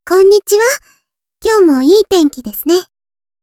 audio_parler_tts_japanese_out_sample_2.wav